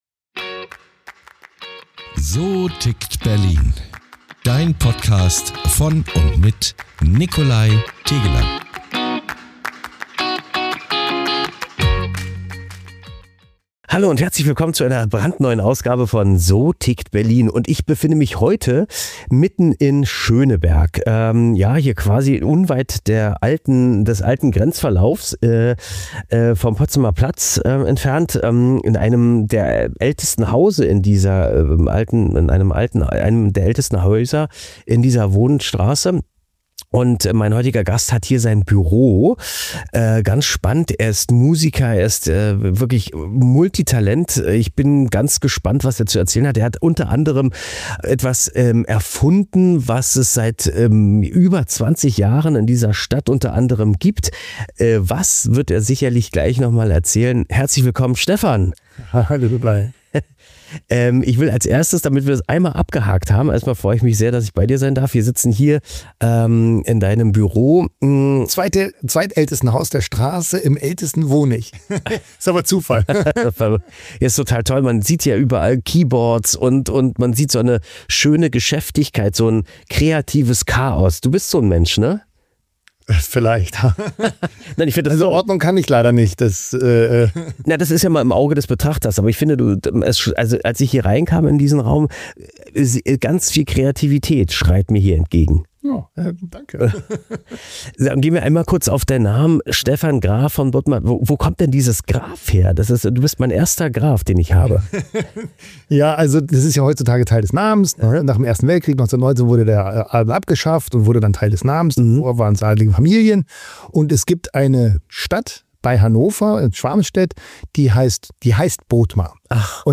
Ein Gespräch über Musik als Erzählkunst, über das Zusammenspiel von Bild und Klang – und über Berlin als Ort, an dem solche künstlerischen Grenzgänge möglich werden.